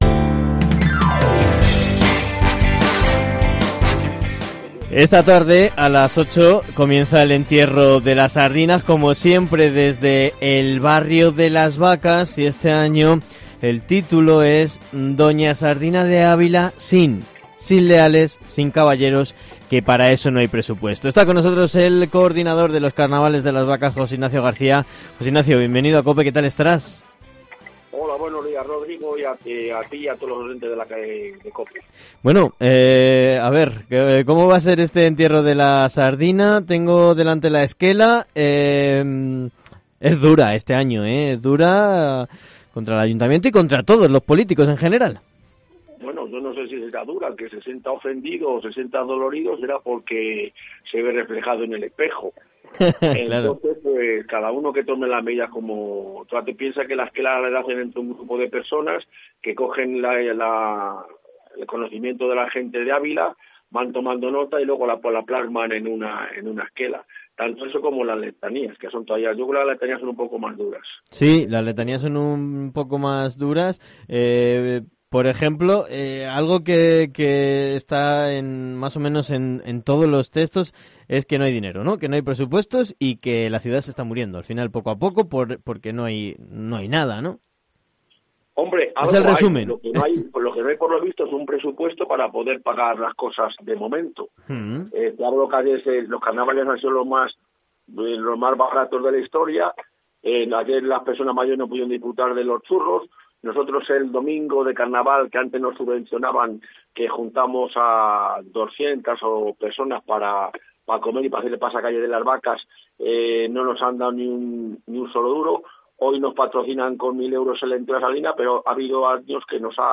ENTREVISTA / Entierro-de-la-sardina-Ávila